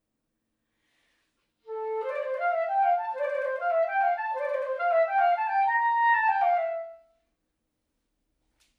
Example 1a: Iwan Müller, Quartet no. 1, I movement, bars 54–56. Played on modern clarinet